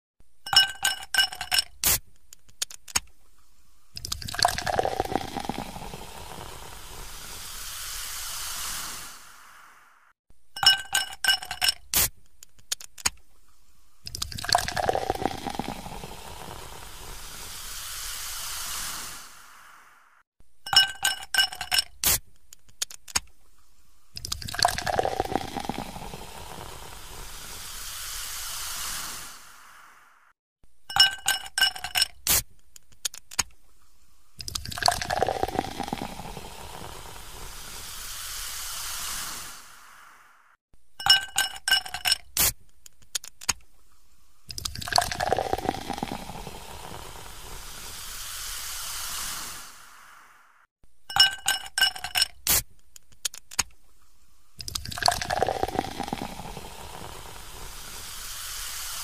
Efeito Sonoro / Abrindo Lata sound effects free download
Efeito Sonoro / Abrindo Lata de Refrigerante